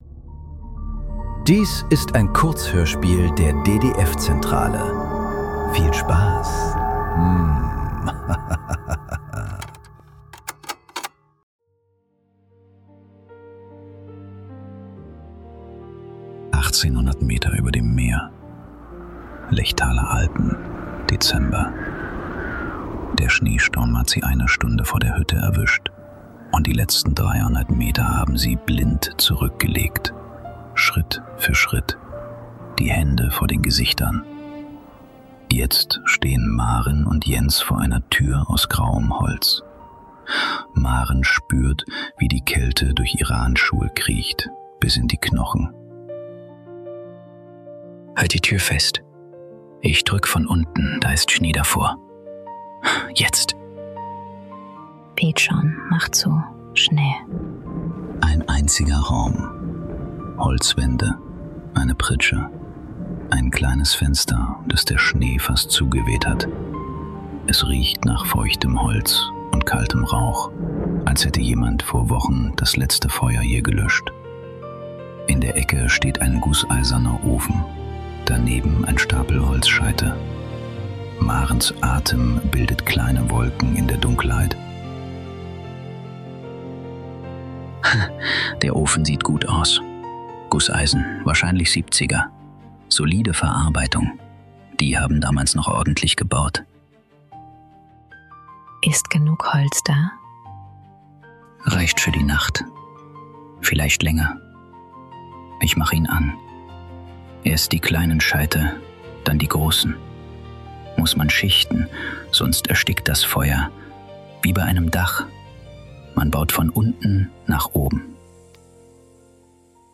Was wir uns versprochen haben ~ Nachklang. Kurzhörspiele.